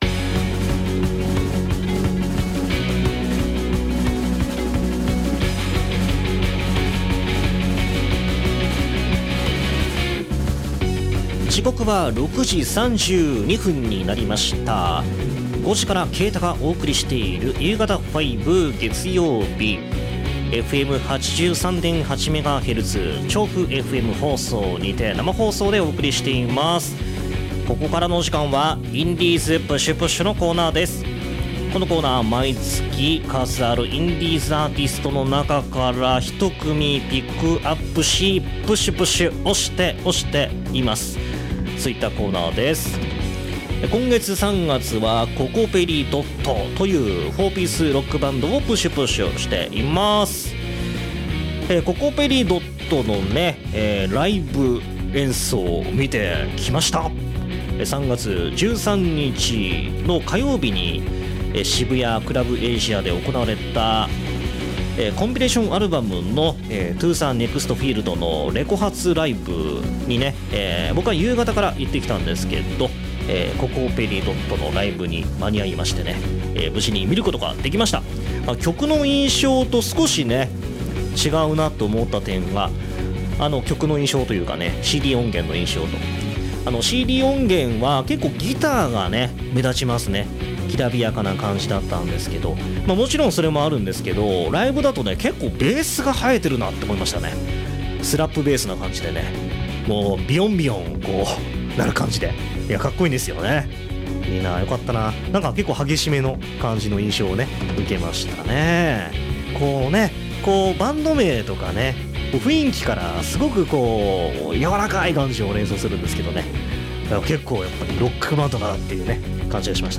4ピースロックバンドです！ 今回も2曲O.Aしましたので音源聴いてください！